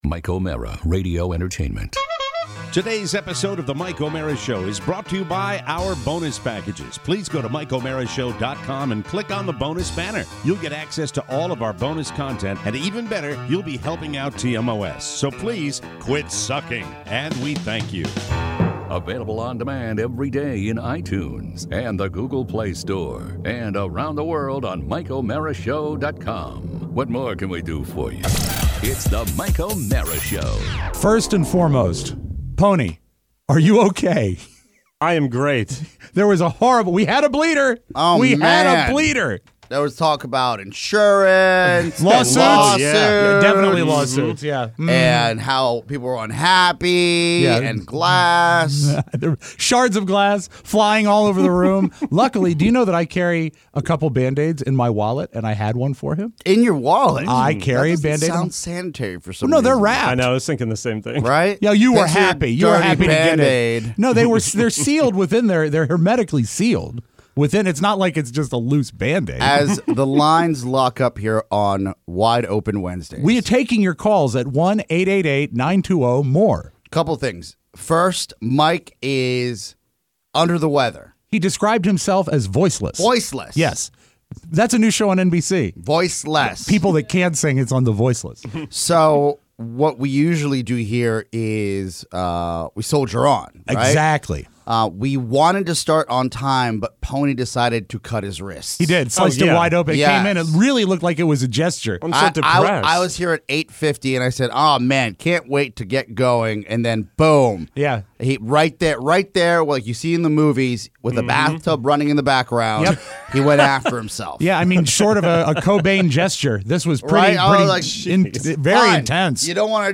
Millennials… and your calls.